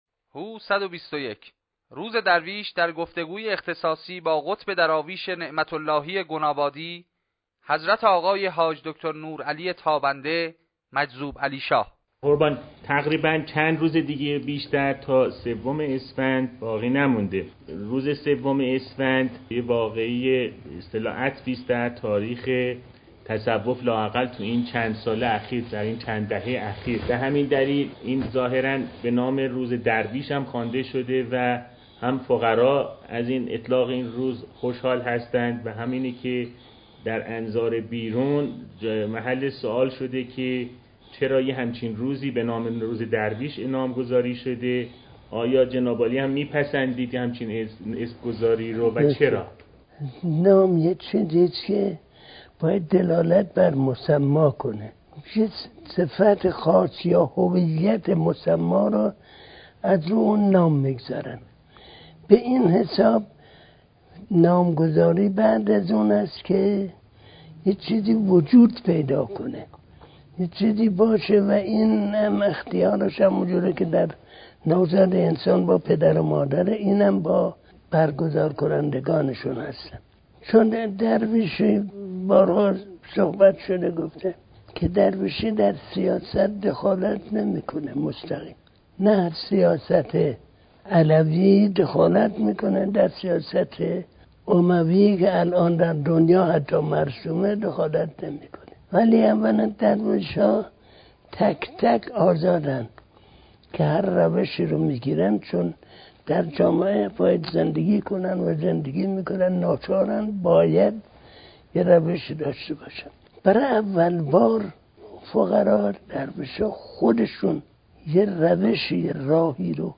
برنامه شماره چهارم: گفتگو